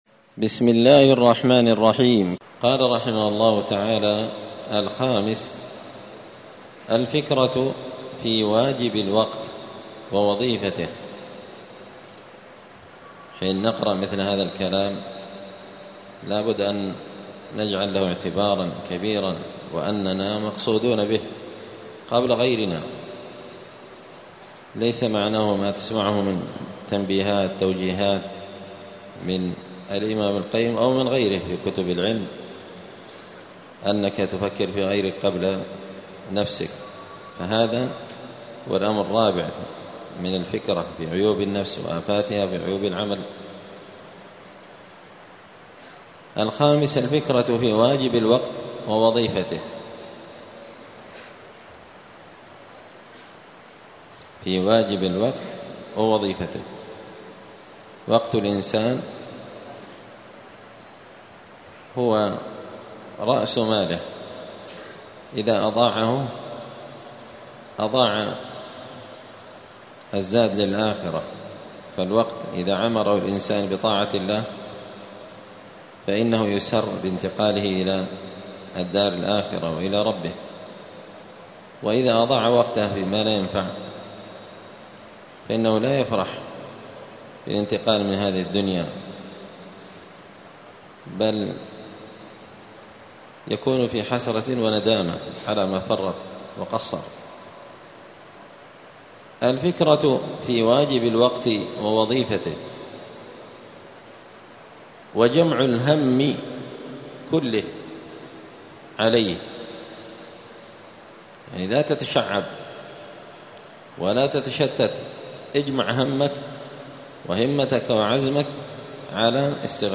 *الدرس التاسع والستون (69) تابع لفصل الخطرة*
دار الحديث السلفية بمسجد الفرقان بقشن المهرة اليمن